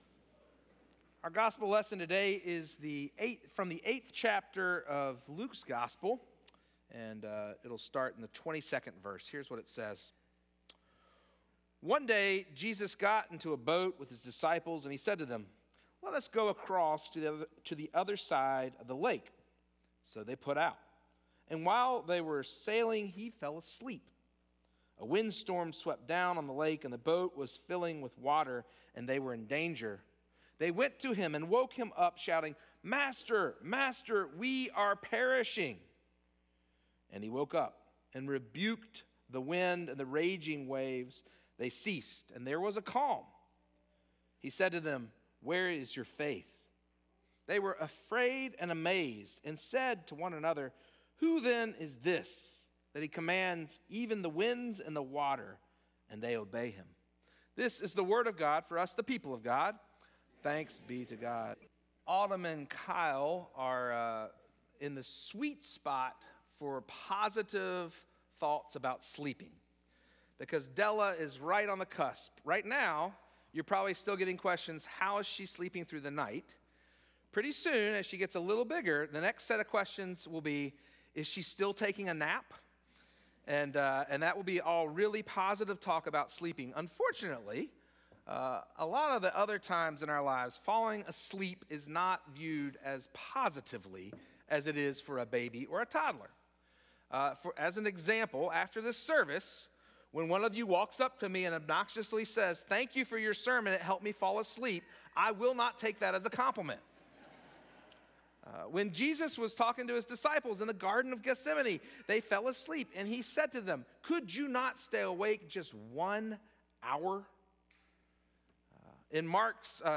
First Cary UMC's First Sanctuary Sermon&nbsp